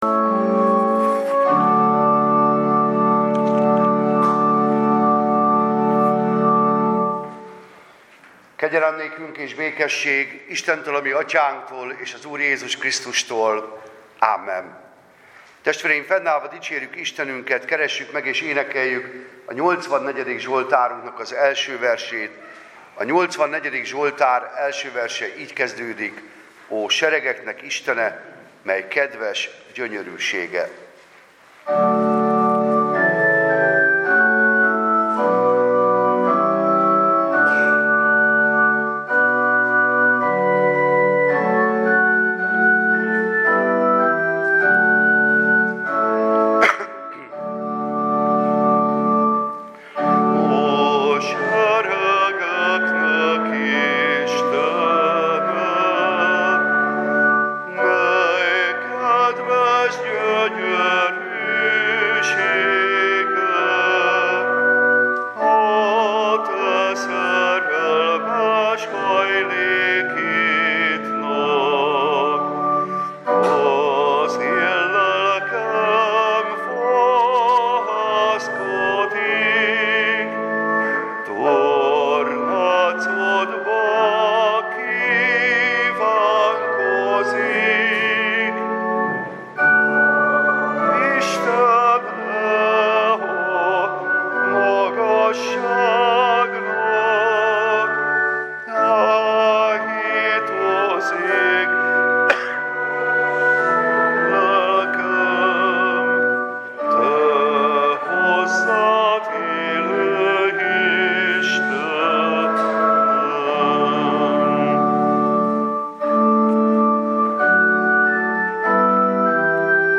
táborzáró istentisztelet